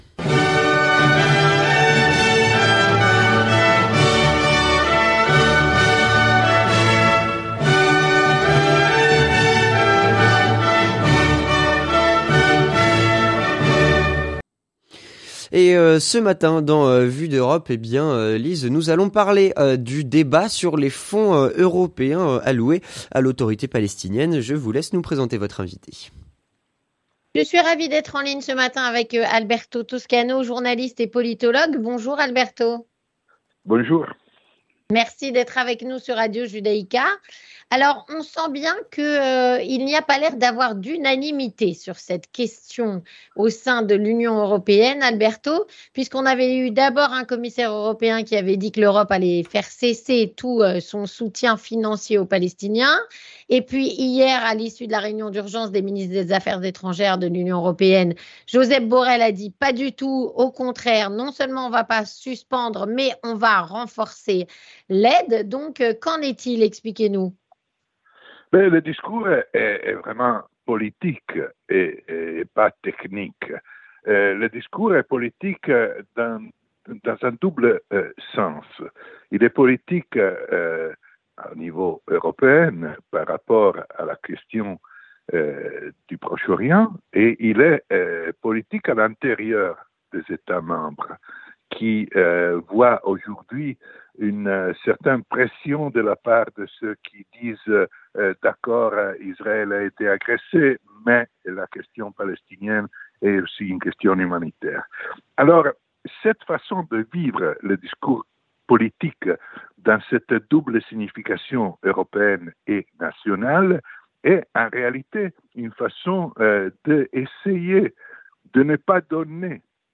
Débat sur les fonds européens destinés à l'autorité palestinienne. Un coup oui, un coup non : où en est-on exactement ?